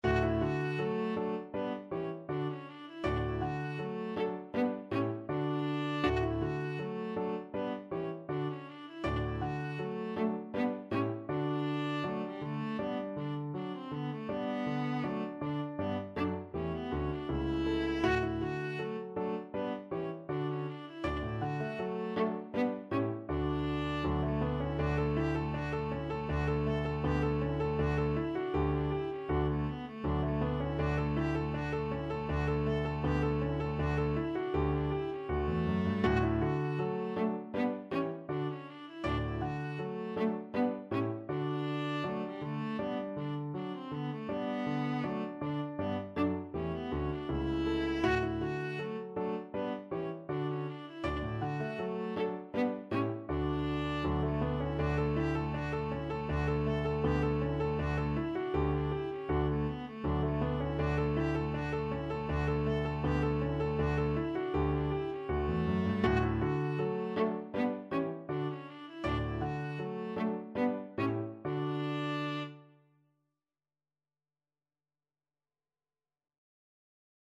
Viola
D major (Sounding Pitch) (View more D major Music for Viola )
4/4 (View more 4/4 Music)
Scherzando =160
G4-A5
Classical (View more Classical Viola Music)
bach_gavotte_2_bwv1012_VLA.mp3